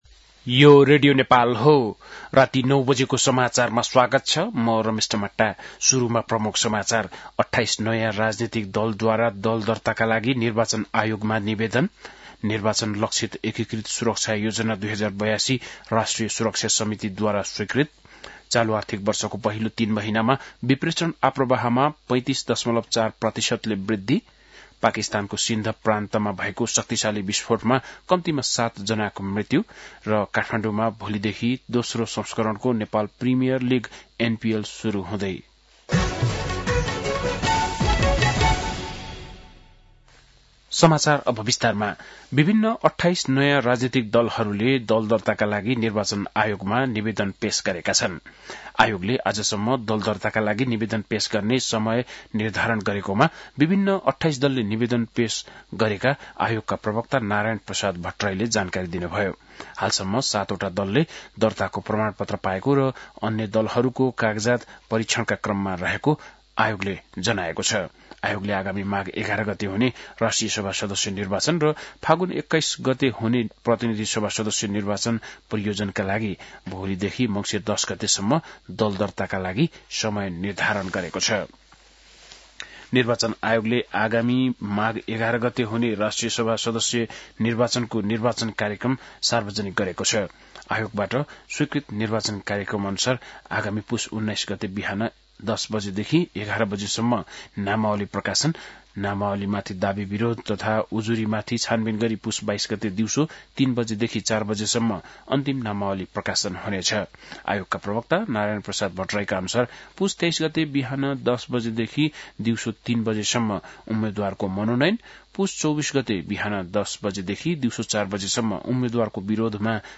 बेलुकी ९ बजेको नेपाली समाचार : ३० कार्तिक , २०८२